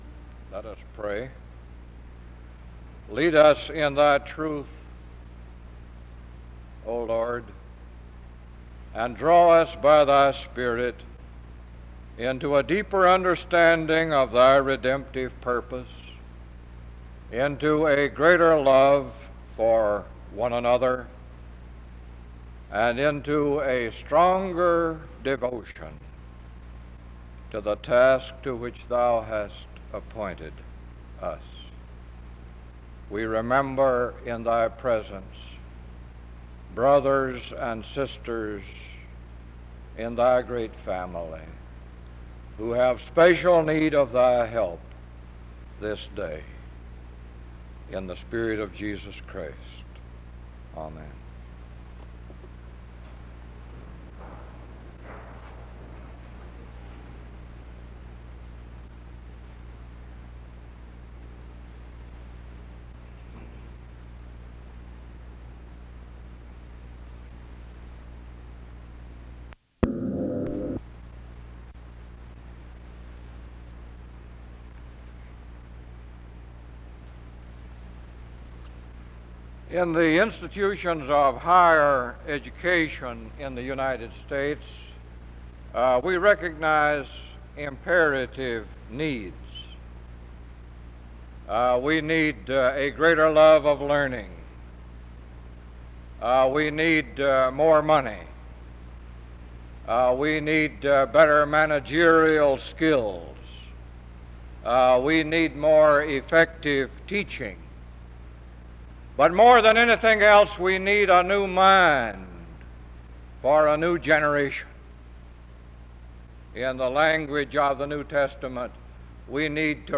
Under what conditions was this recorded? SEBTS Chapel The service begins with a prayer (0:00-0:52).